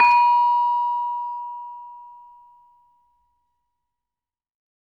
LAMEL A#4 -R.wav